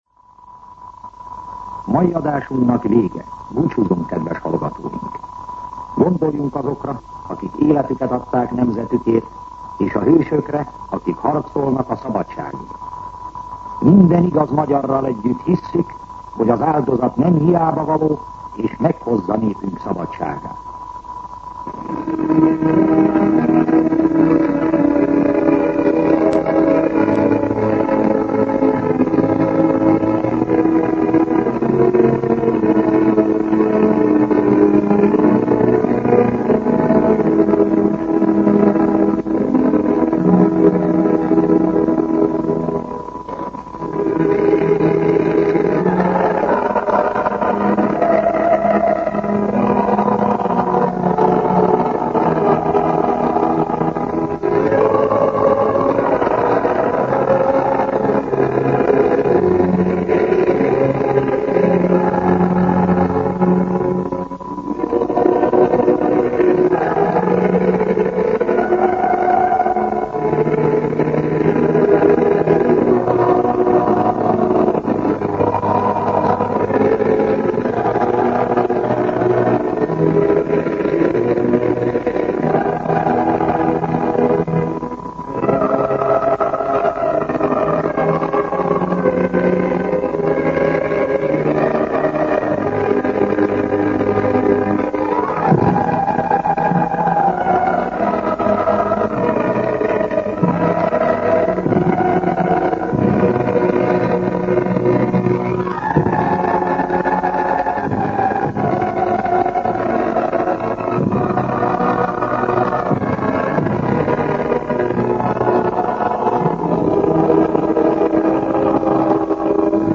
Műsorzárás